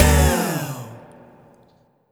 Vocal Hit Wband-D3.wav